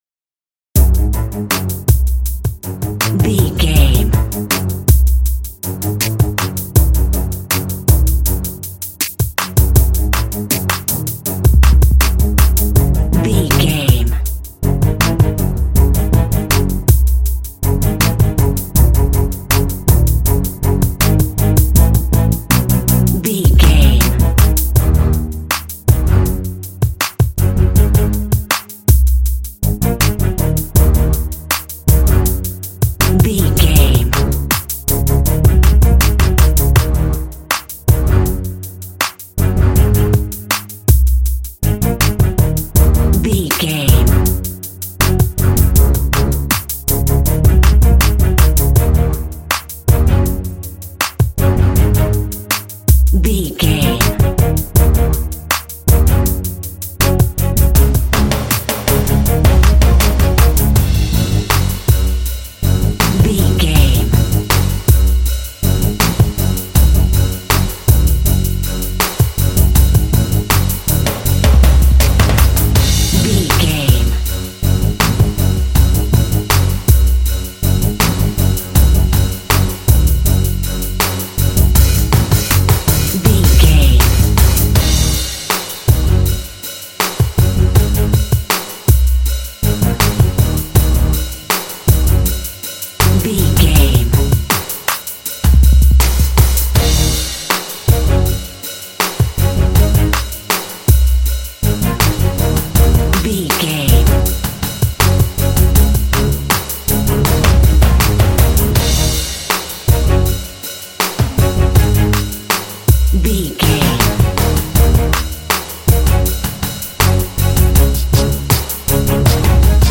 Fast paced
Aeolian/Minor
F#
ominous
tension
drums
strings